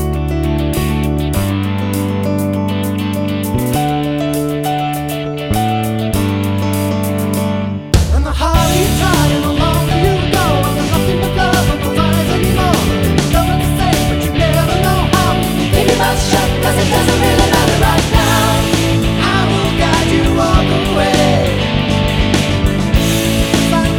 Minus Piano Rock 8:08 Buy £1.50